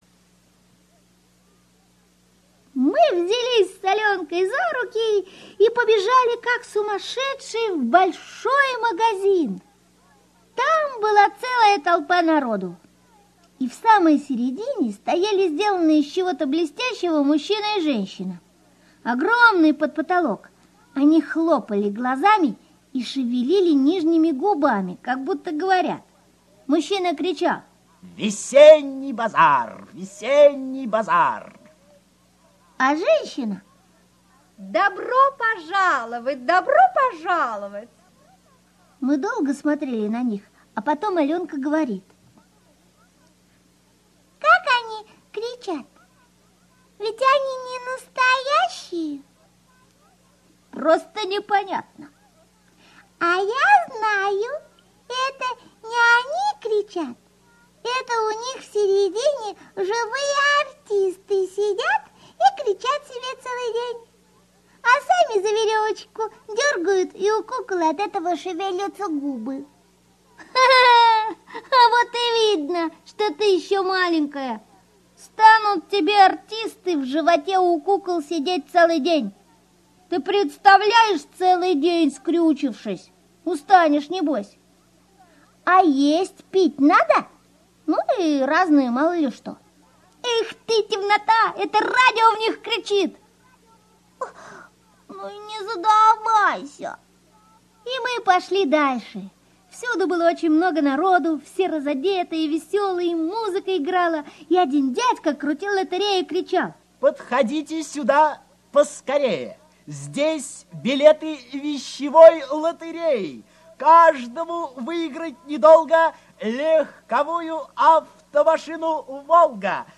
Слушайте онлайн Красный шарик в синем небе - аудио рассказ Драгунского В.Ю. История о том, как Дениска с Аленкой ходили на весенний базар.